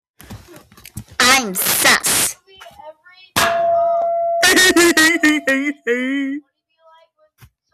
GLOOPEEP Sound Effect - voice record soundboard Meme Sounds | FunSoundboard - Free Meme SoundBoard & Viral Audio Clips